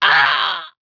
loselimb1.wav